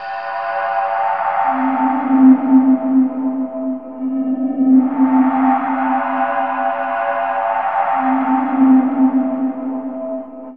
Index of /90_sSampleCDs/Chillout (ambient1&2)/13 Mystery (atmo pads)
Amb1n2_u_pad_c.wav